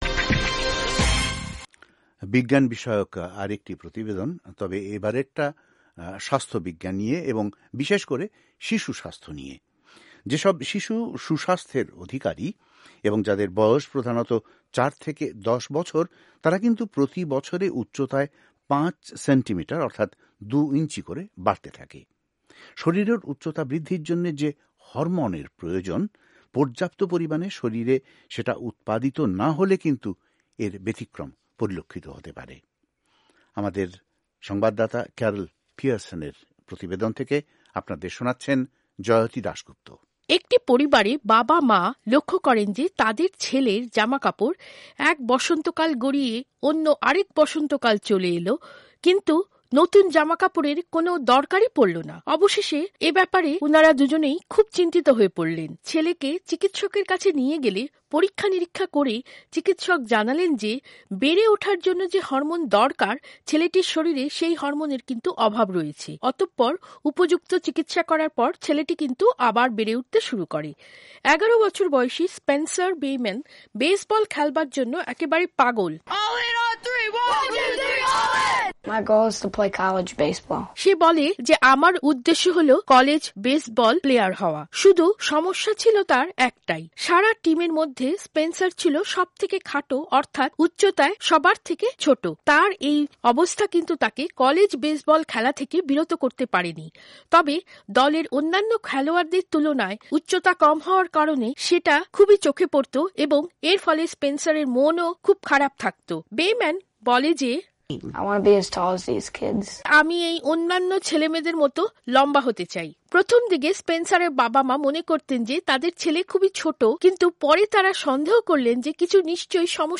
আজকের স্বাস্থ্য ও বিজ্ঞান পর্বে প্রতিবেদনটি পড়ে শোনাচ্ছেন